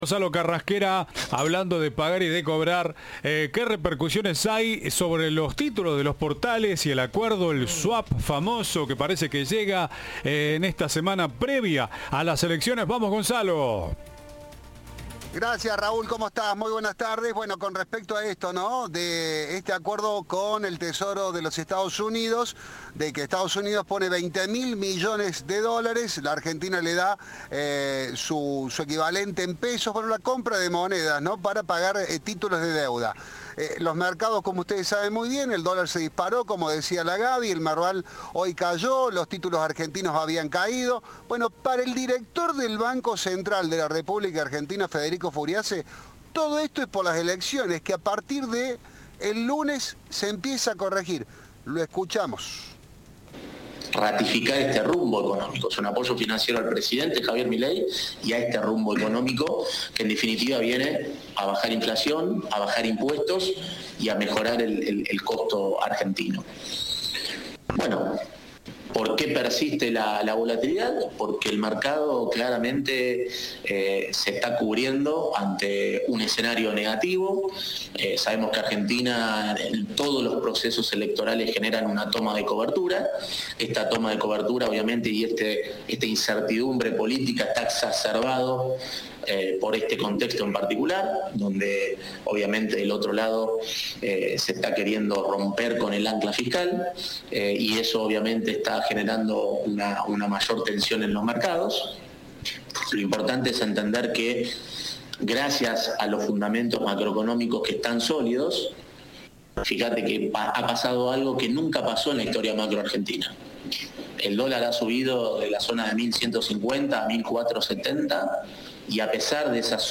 Federico Furiase aseguró a Cadena 3 que la incertidumbre se relaciona con los comicios, pero los fundamentos macroeconómicos "siguen siendo sólidos".